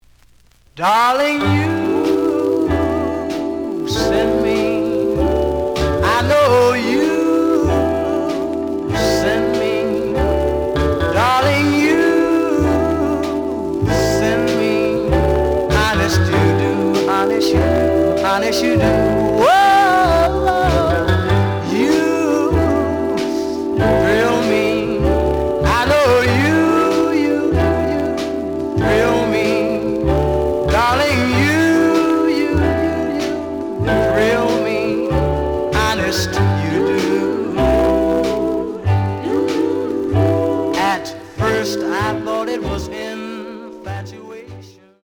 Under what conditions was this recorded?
The audio sample is recorded from the actual item. Slight noise on both sides.)